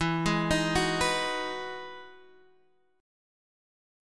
E Major 9th
EM9 chord {x x 2 1 4 2} chord